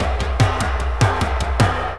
TOM FILL 2-L.wav